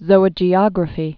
(zōə-jē-ŏgrə-fē)